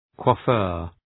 Προφορά
{kwɒ’fjʋr}